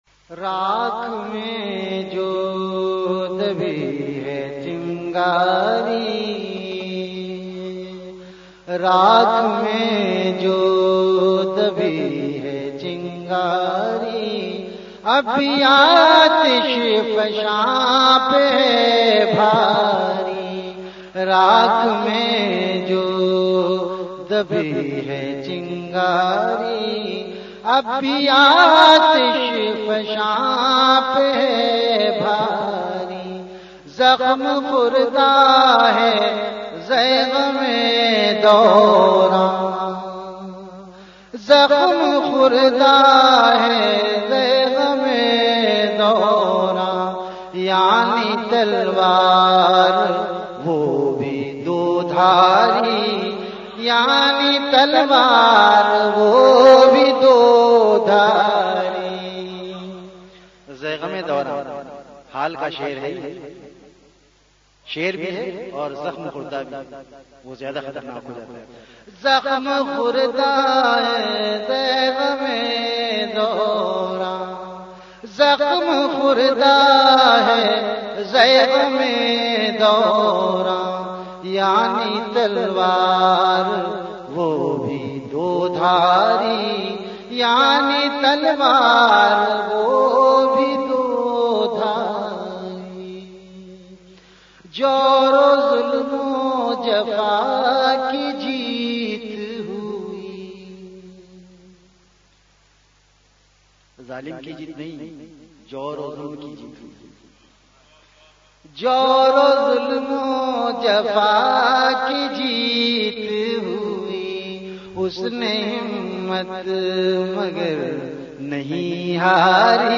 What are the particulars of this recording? VenueKhanqah Imdadia Ashrafia Event / TimeAfter Isha Prayer